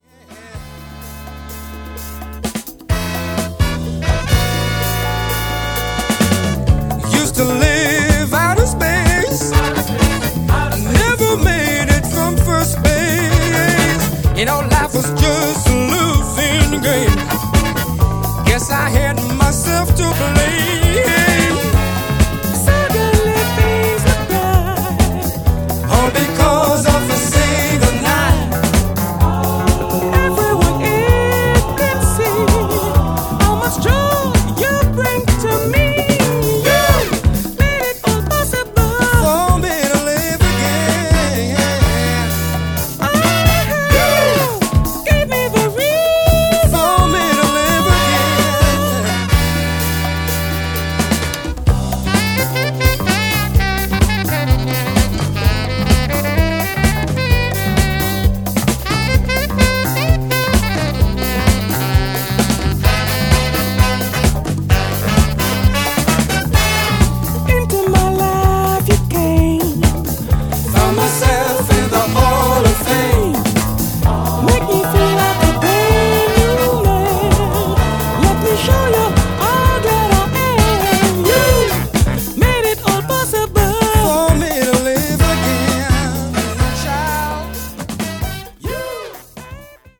'77年にロンドンに録音されるもお蔵入りになっていたスタジオ・セッションが初の7"化。'
New Release Disco Classics Soul / Funk